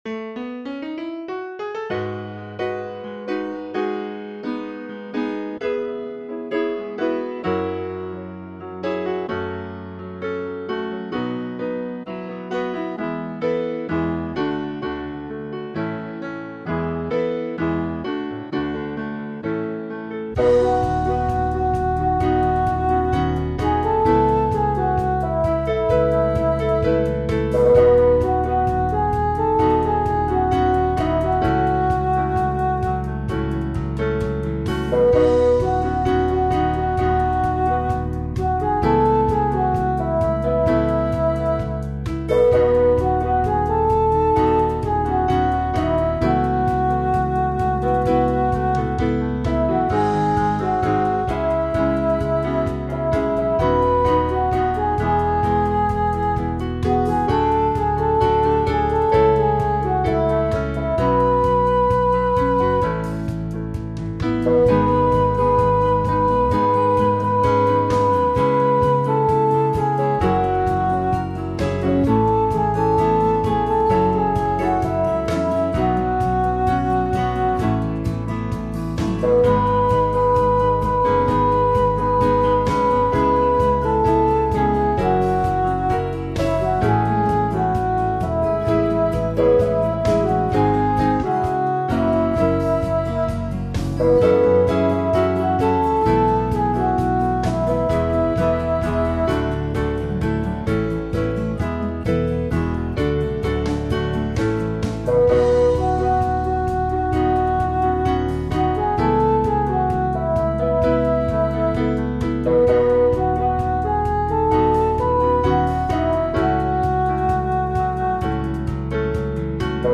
a song for solo devotional use
My backing hasn’t quite got the correct unhurried vibe.